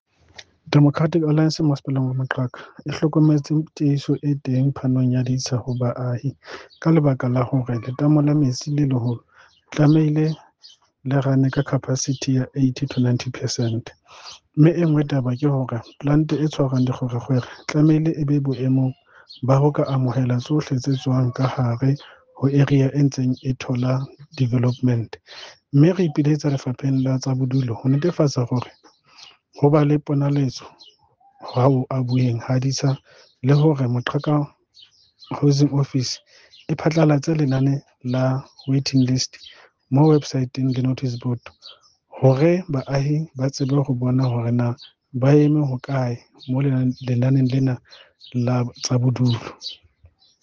Sesotho soundbites by Cllr Sepatala Chabalala.